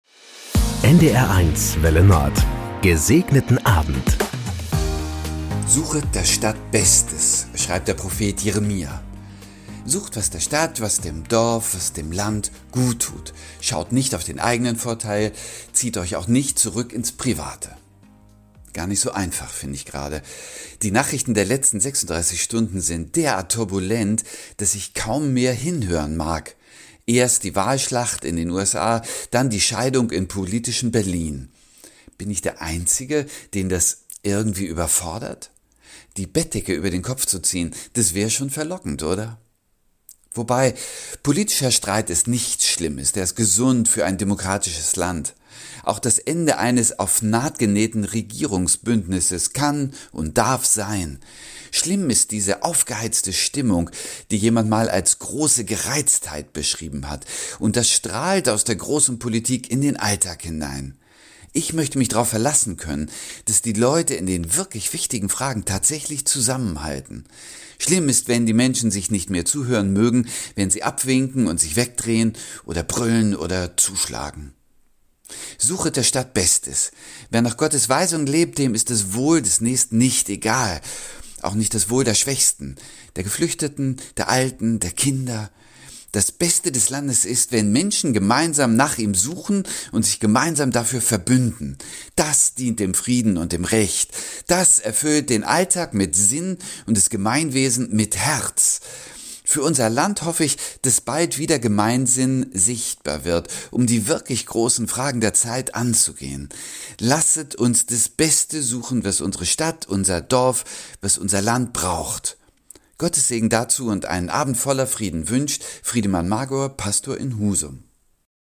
Das gute Wort zum Feierabend auf NDR 1 Welle Nord mit den Wünschen für einen "Gesegneten Abend". Von Sylt oder Tönning, Kiel oder Amrum kommt die Andacht als harmonischer Tagesabschluss.